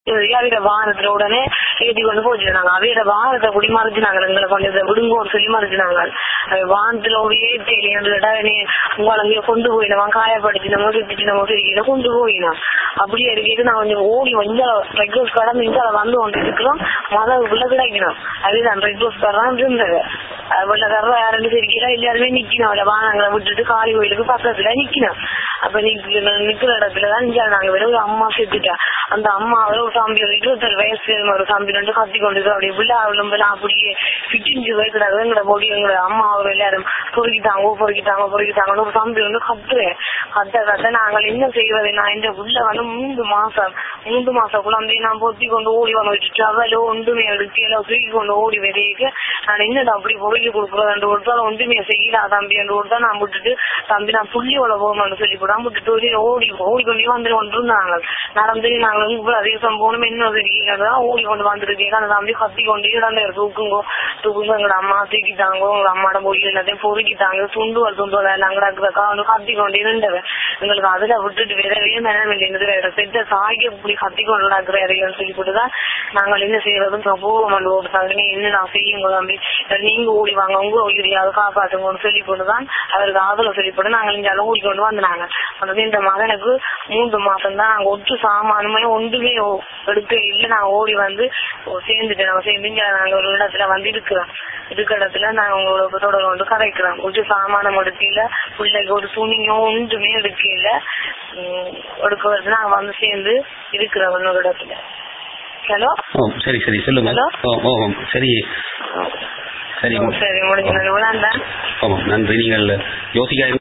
[EYEWITNESS ACCOUNT: AUDIO]
TamilNet publishes direct eyewitness accounts from the street of Udaiyaarkaddu.